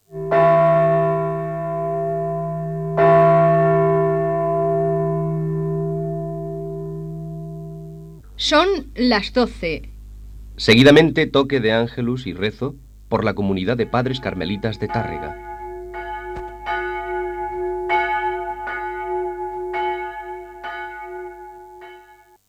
Hora i anunci del res de l'Àngelus, des del convent del Carme de Tàrrega, pels pares Carmelites